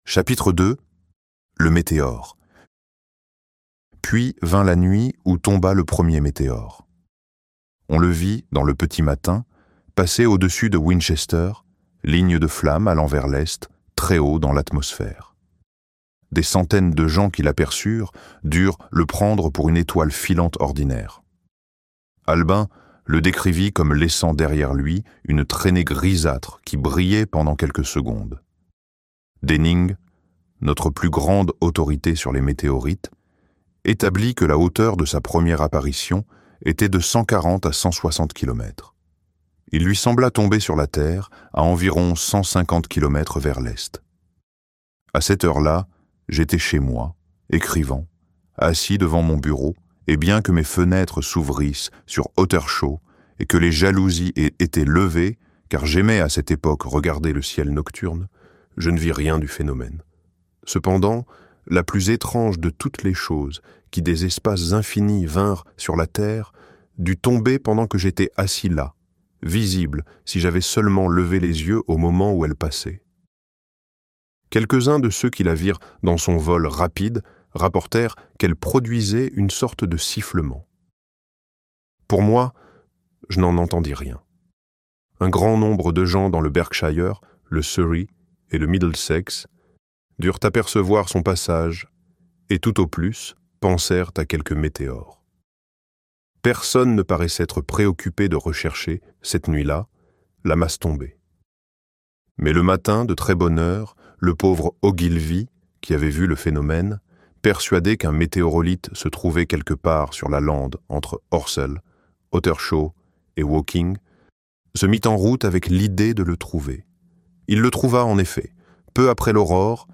La Guerre des mondes - Livre Audio